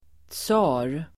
Ladda ner uttalet
tsar substantiv (historiskt), czar [historical]Uttal: [tsa:r] Böjningar: tsaren, tsarerSynonymer: furste, kejsare, konung, statsöverhuvudDefinition: kejsare i Ryssland